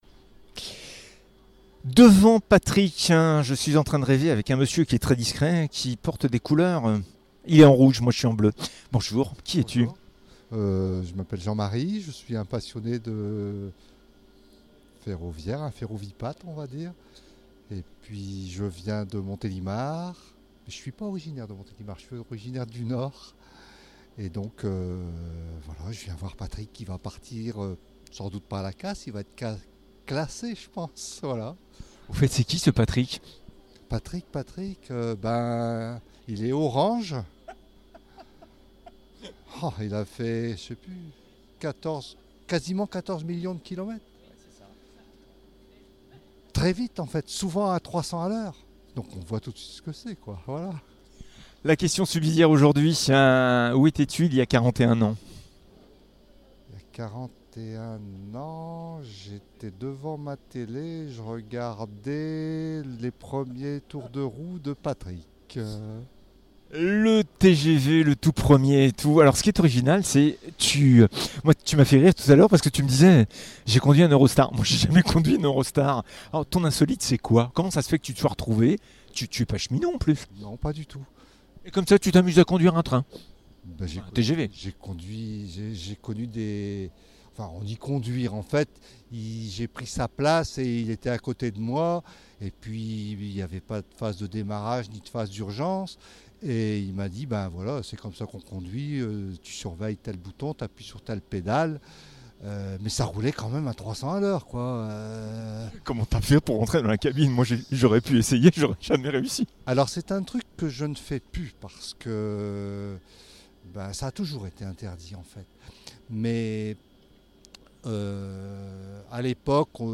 Tout est dit dans l'interview ... "Insolite"